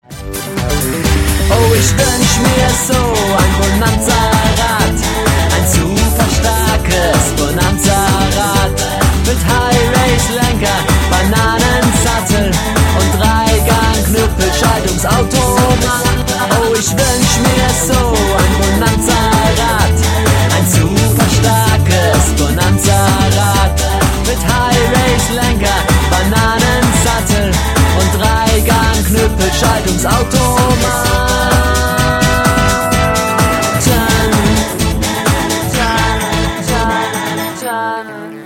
MIt flotten neuen Beats